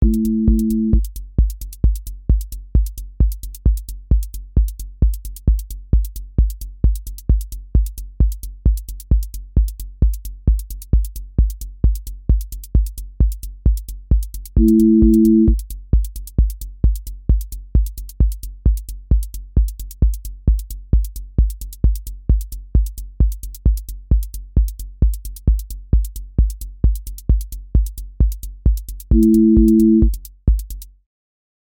QA Listening Test techno Template: techno_hypnosis
• voice_kick_808
• voice_hat_rimshot
• voice_sub_pulse
• tone_brittle_edge
• motion_drift_slow